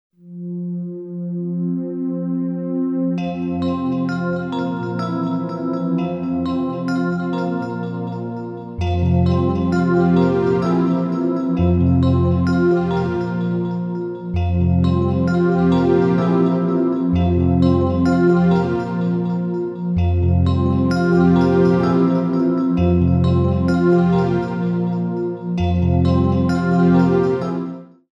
Synthesizers